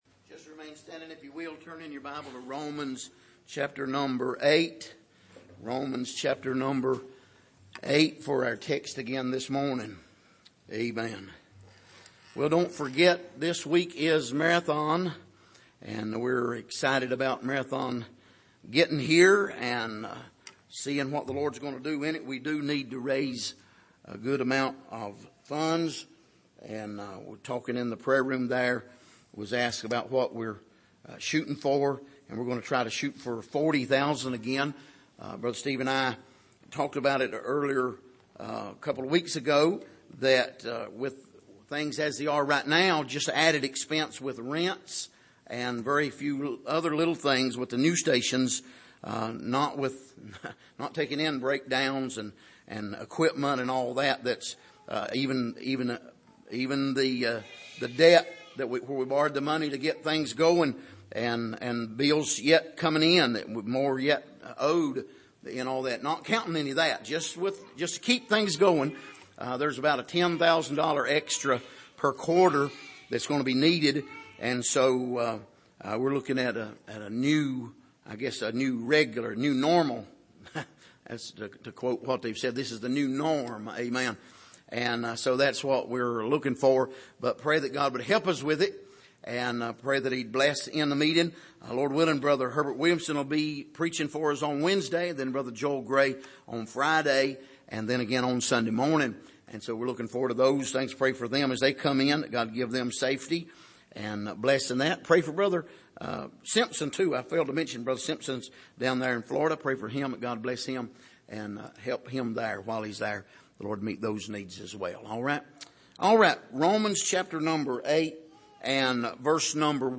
Passage: Romans 8:1-4 Service: Sunday Morning